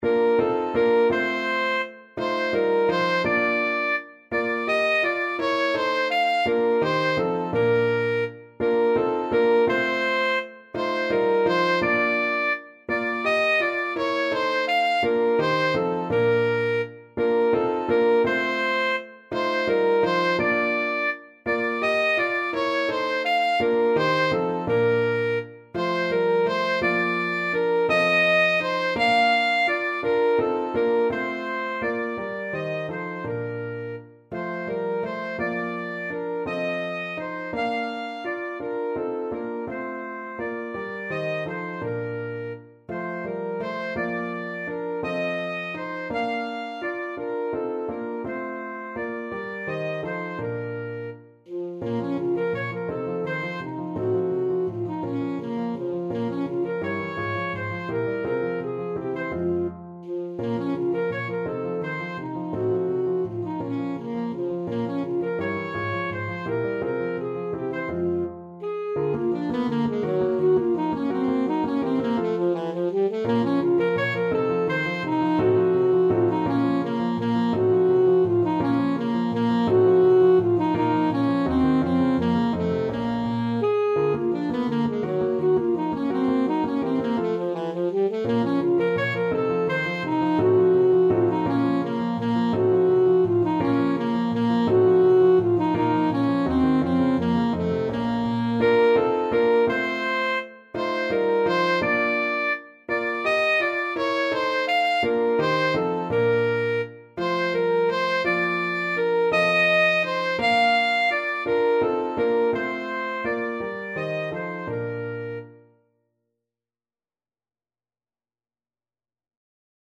Alto Saxophone
3/8 (View more 3/8 Music)
Lustig (Happy) .=56
Classical (View more Classical Saxophone Music)